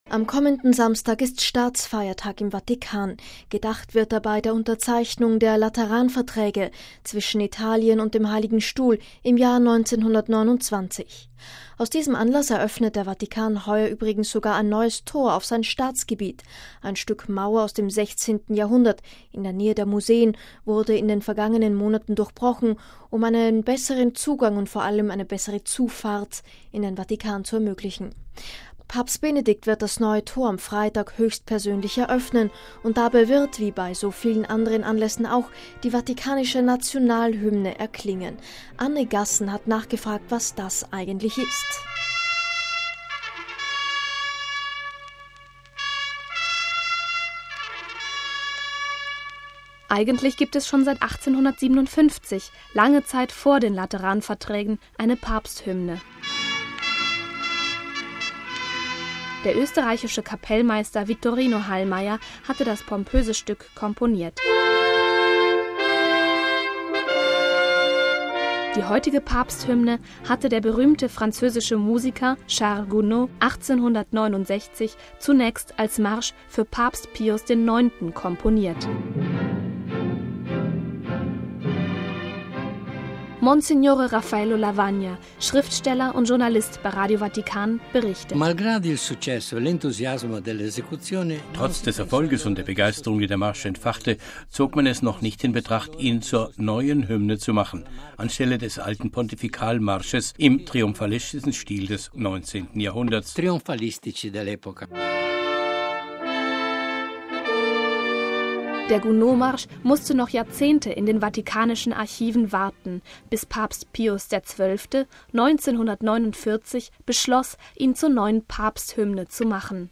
Audio-Dossier: Die Vatikan-Hymne